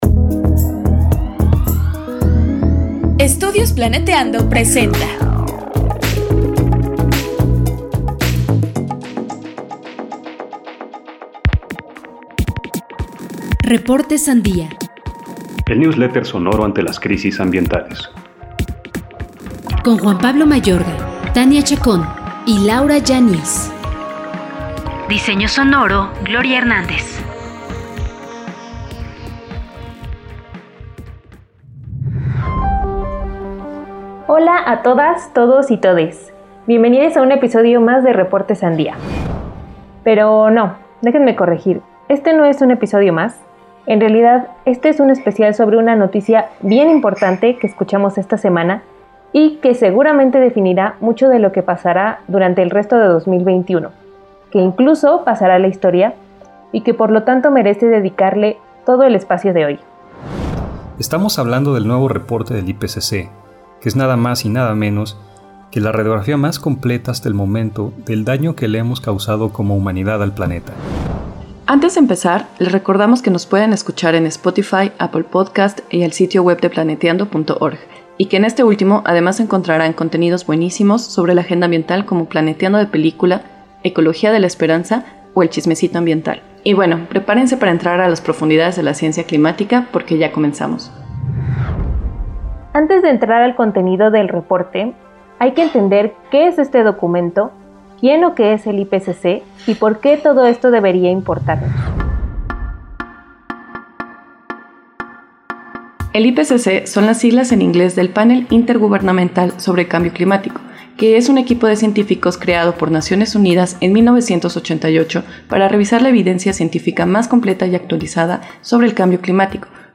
Entrevistamos a cuatro expertos para comprender el informe.